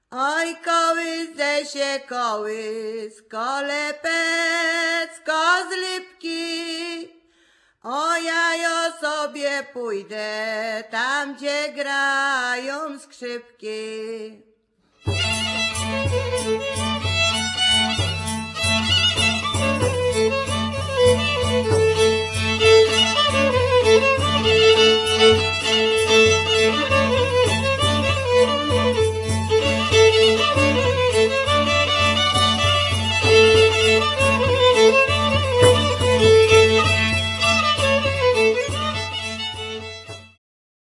skrzypce fiddle, cymbały dulcimer, śpiew voice
bębenek tambourine, baraban baraban drum, basy bass
flet drewniany wooden flute, szałamaja shawm